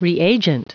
Prononciation du mot reagent en anglais (fichier audio)
Prononciation du mot : reagent